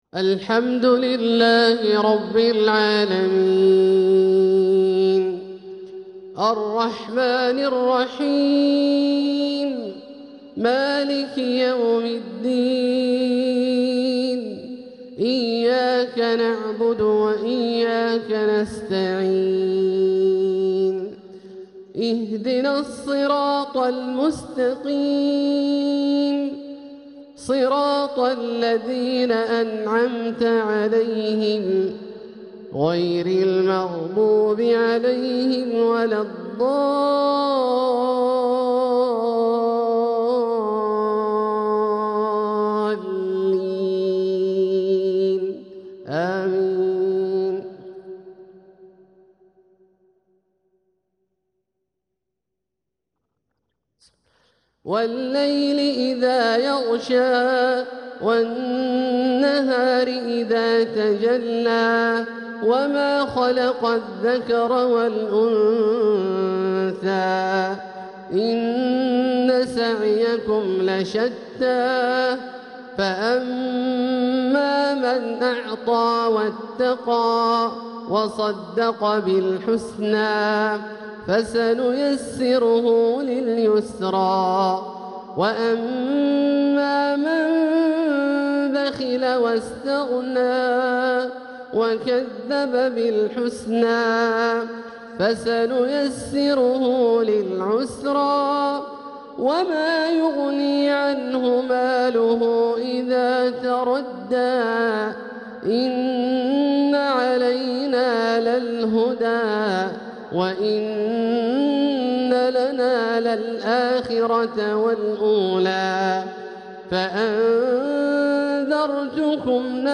تلاوة لسورتي الليل و الضحى كاملة | عشاء الخميس 24 ربيع الآخر 1447هـ > ١٤٤٧هـ > الفروض - تلاوات عبدالله الجهني